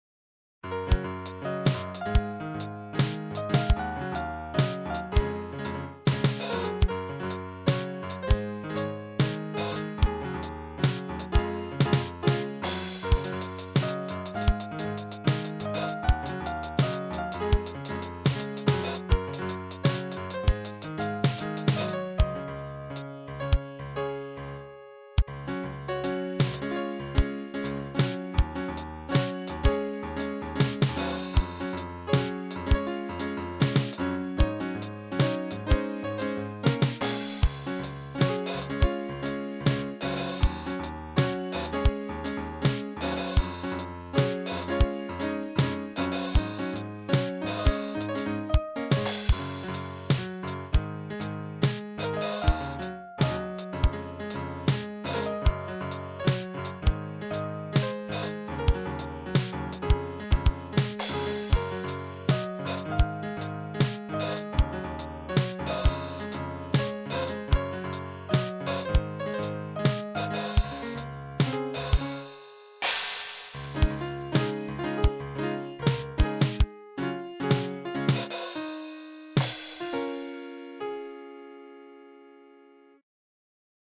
Merry tune this time with Groove and Drums and Piano